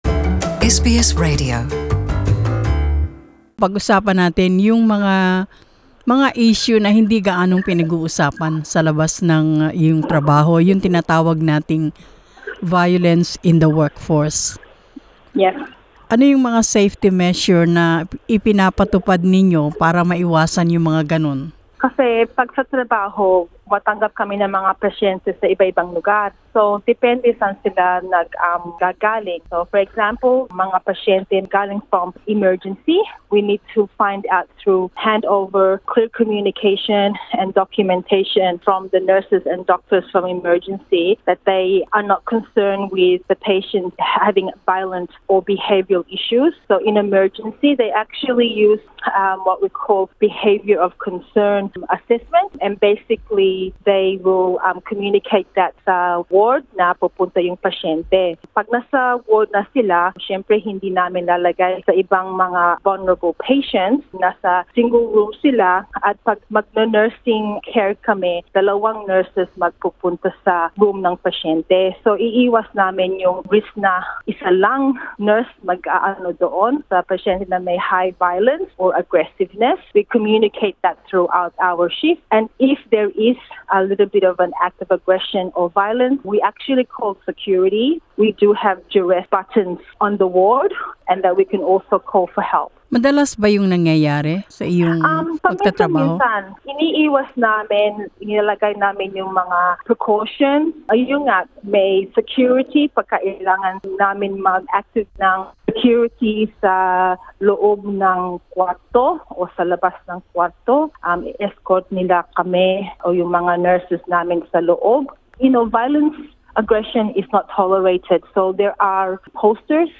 Sa panayam na ito tinalakay niya ang mga estratehiya at pamamaraang pangkaligtasan na ginagamit ng mga kawani sa pagharap sa karahasan sa trabaho.